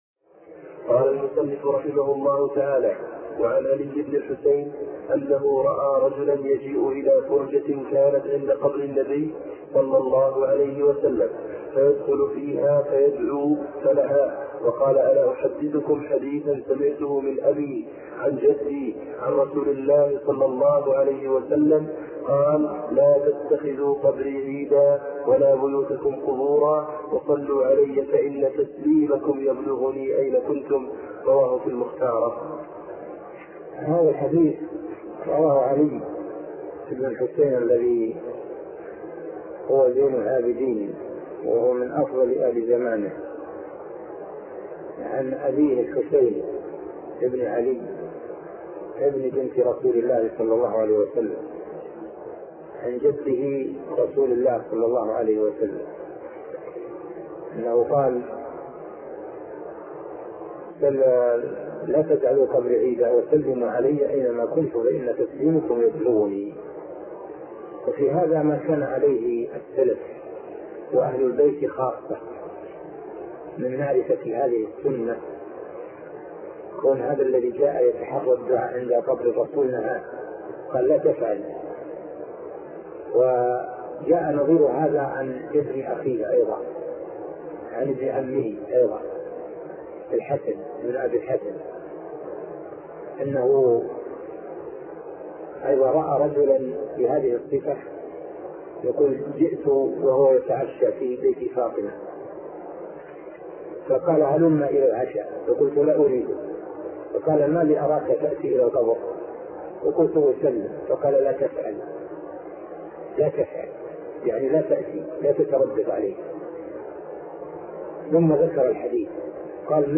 عنوان المادة الدرس ( 68) شرح فتح المجيد شرح كتاب التوحيد تاريخ التحميل الجمعة 16 ديسمبر 2022 مـ حجم المادة 29.07 ميجا بايت عدد الزيارات 209 زيارة عدد مرات الحفظ 109 مرة إستماع المادة حفظ المادة اضف تعليقك أرسل لصديق